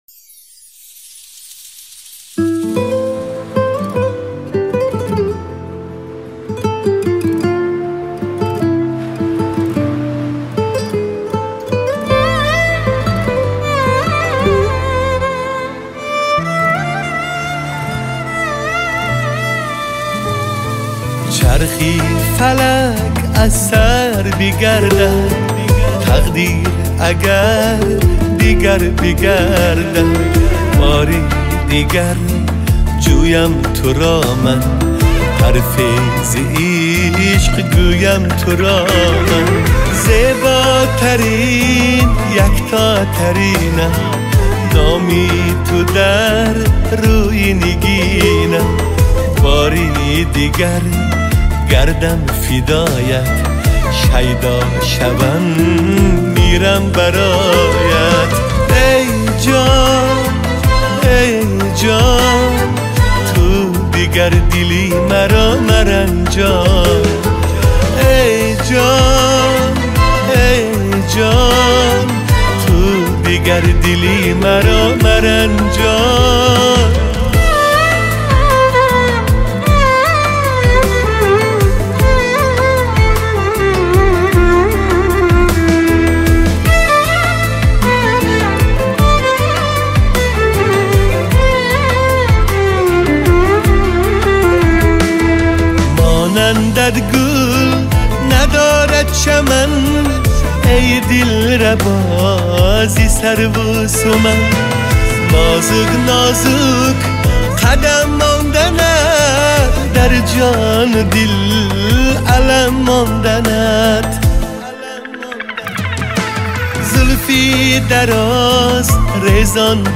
Категория: Таджикские